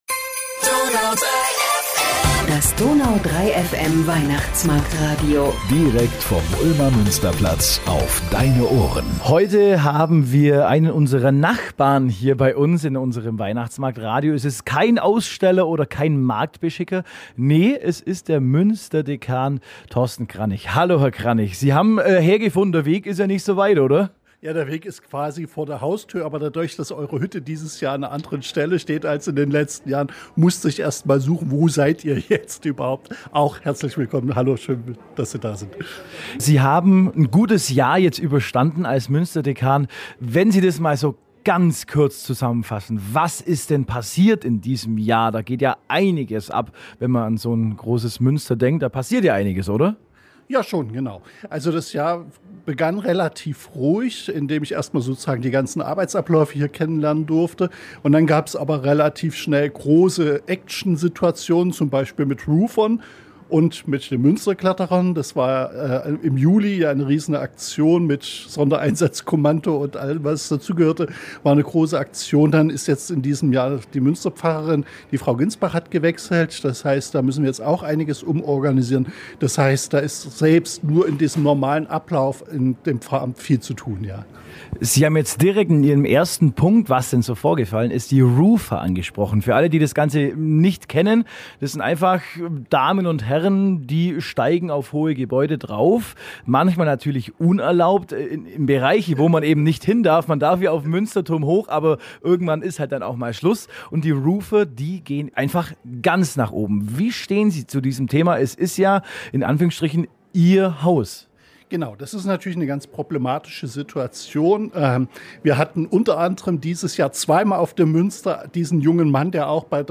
Ein direkter Nachbar zu Gast im DONAU 3 FM Weihnachtsmarktradio.